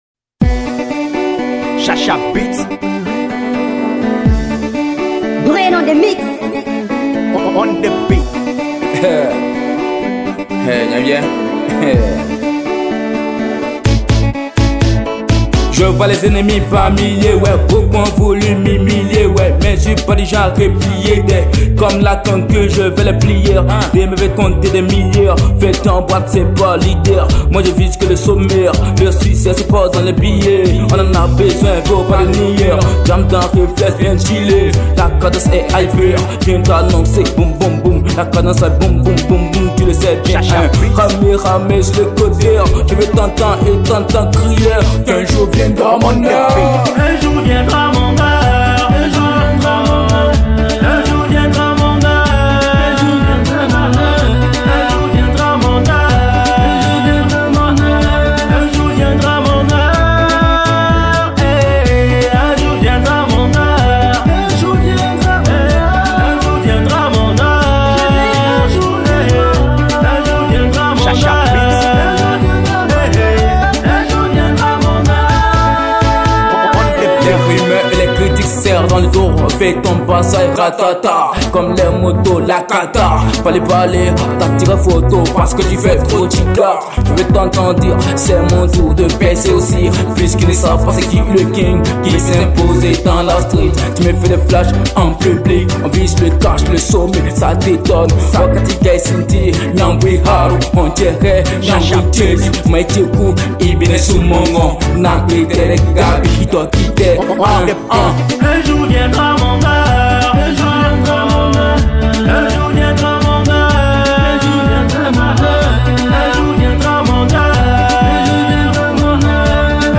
| RnB